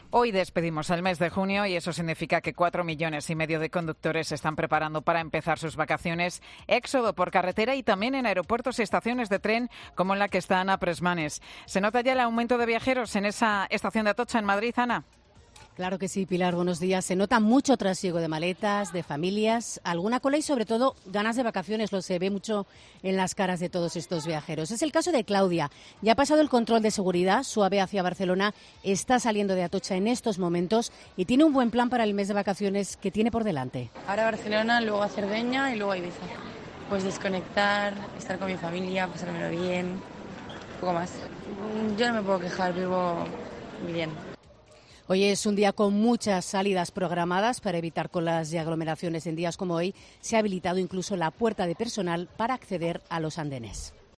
Operación salida del verano: Escucha la crónica